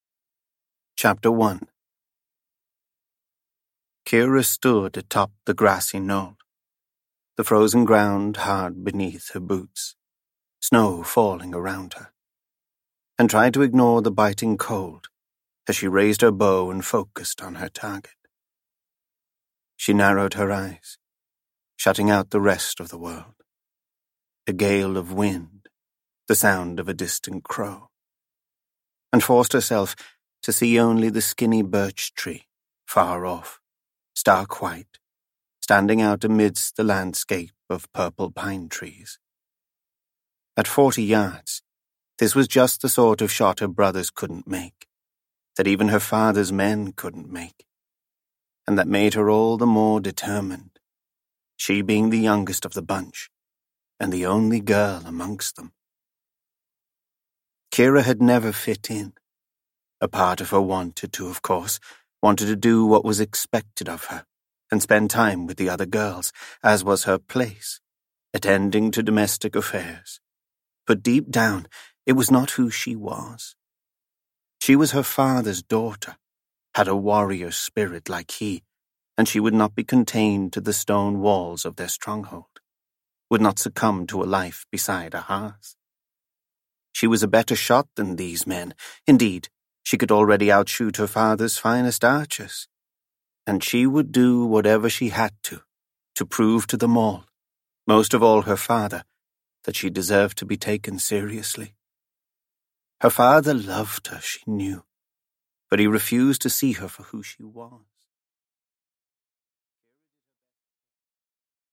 Аудиокнига Kings and Sorcerers Bundle | Библиотека аудиокниг